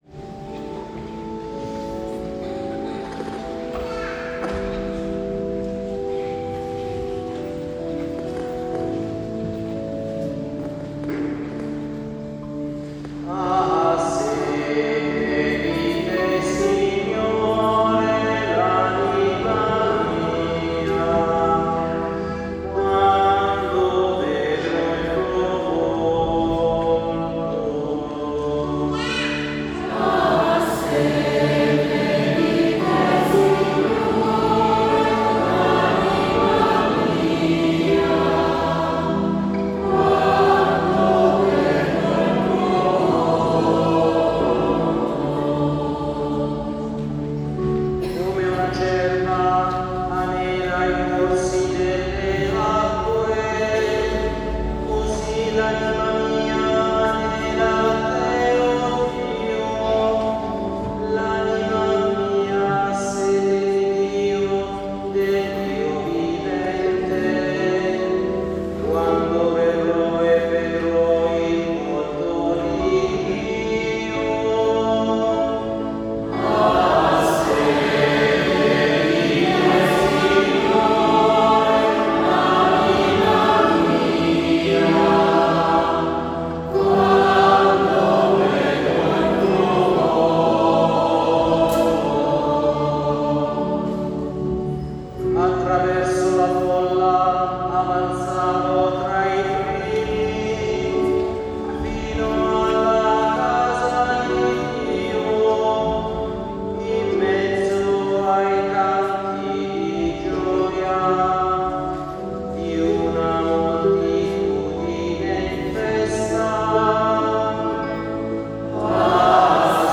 19 aprile 2025 - Notte di Pasqua
Organo
Chitarra
Cimbaletti
Bonghi